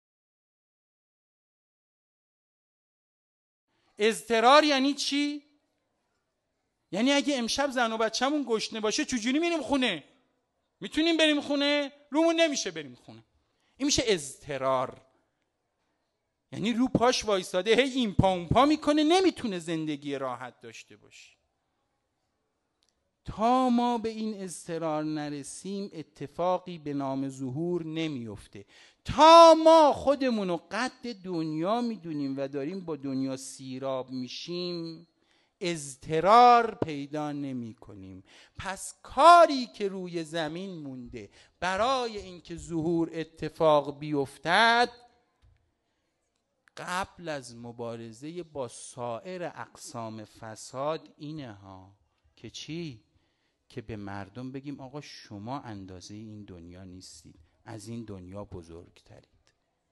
بخشی از سخنرانی
شب نهم محرم 1397 - هیأت دانشجویی خادمان مهدی (عج) قزوین